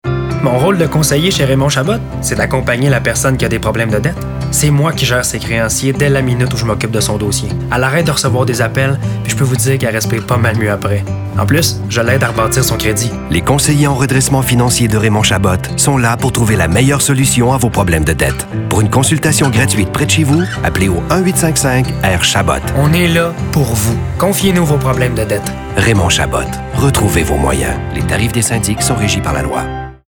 Pour Raymond Chabot, leader de cette industrie au Québec, nous avons plutôt adoptés un ton calme, respectueux et qui invite à la confidence.
Radios